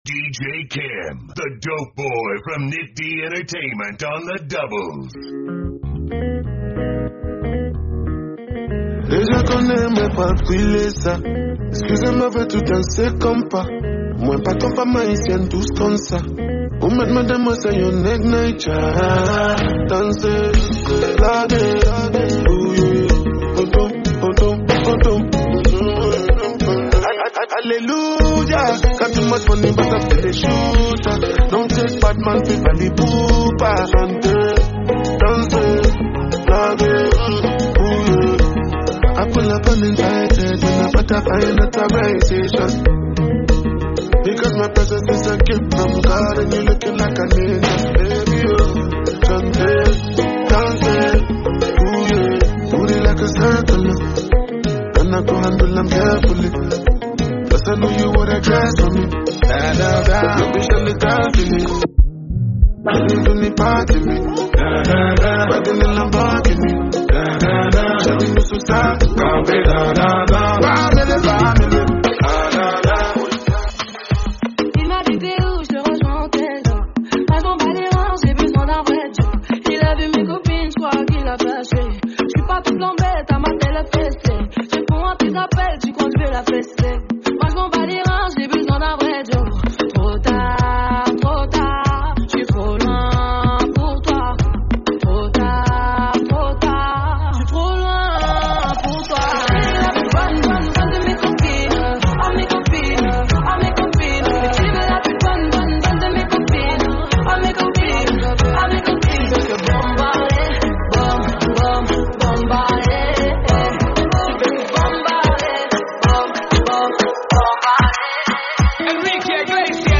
(Live at The Location Rooftop, Nairobi)
Afrobeat